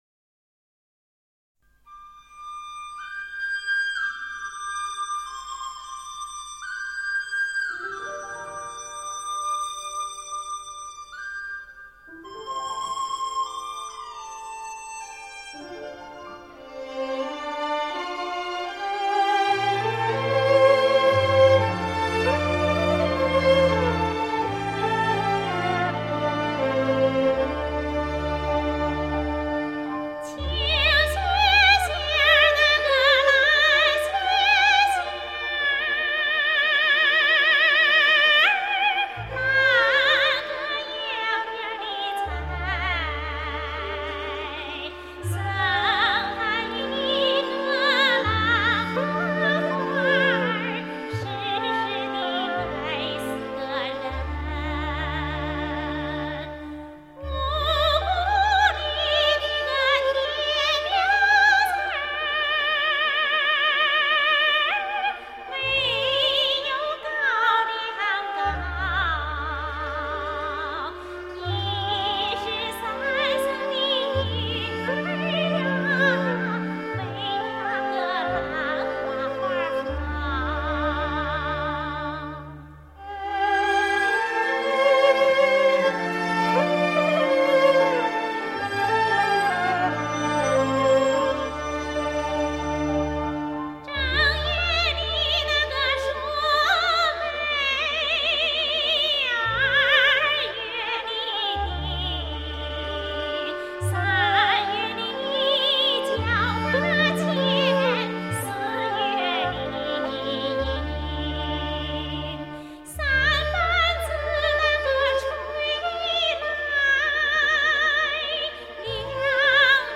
陕北民歌